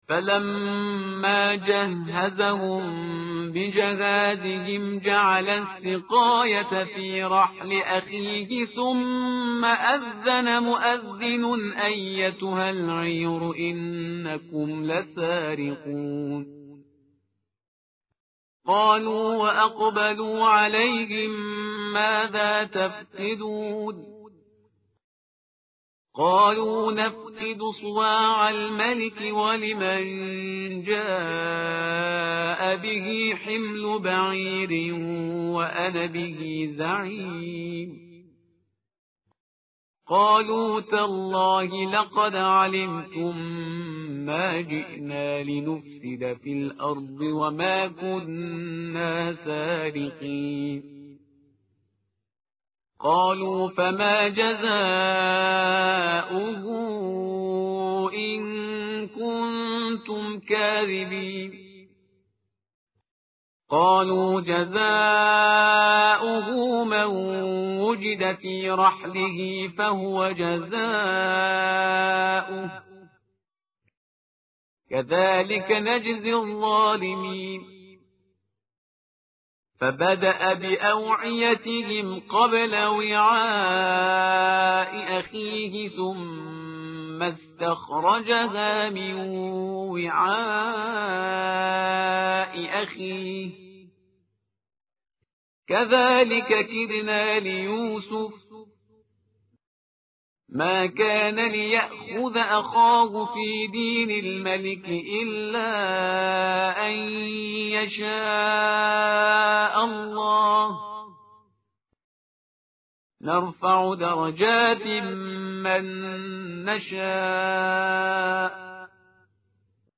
tartil_parhizgar_page_244.mp3